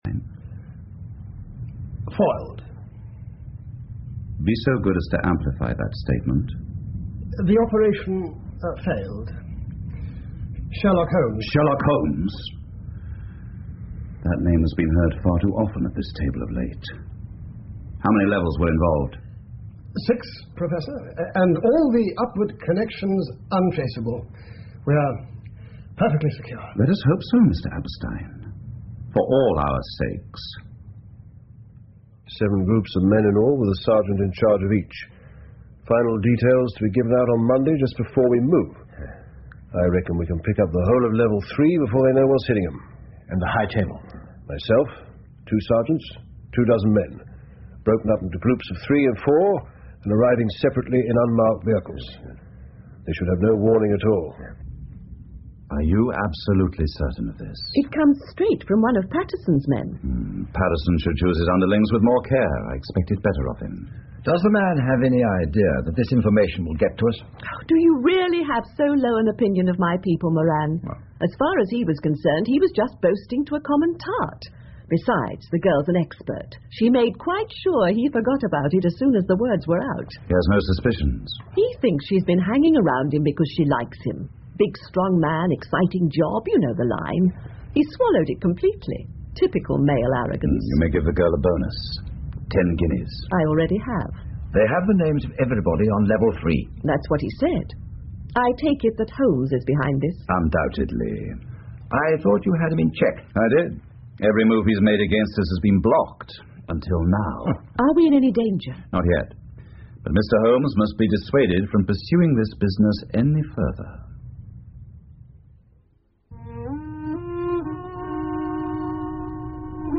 福尔摩斯广播剧 The Final Problem 2 听力文件下载—在线英语听力室